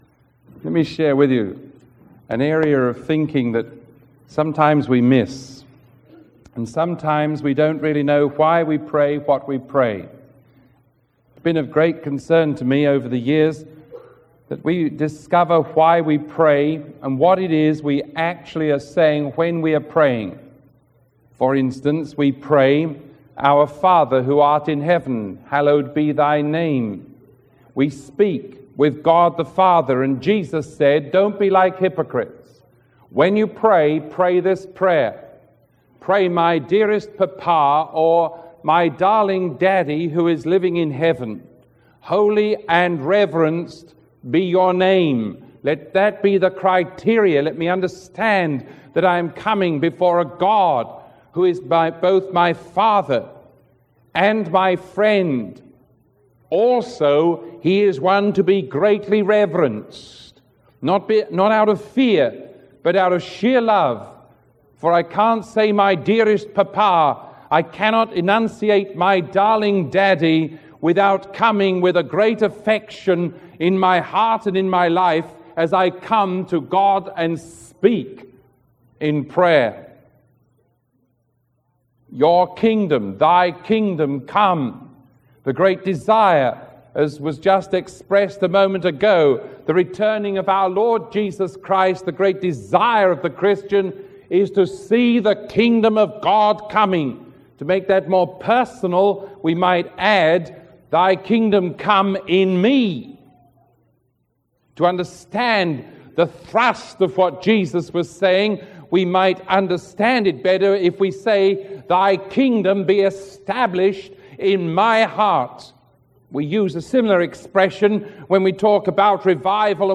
Sermon 0930A